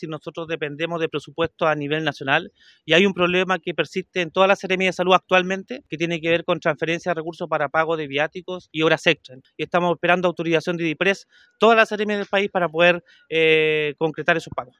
Consultado por Radio Bío Bío, el seremi de Salud, Andrés Cuyul, reconoció esta demanda, apuntando que están a la espera de una resolución del nivel central para realizar los pagos en cuestión.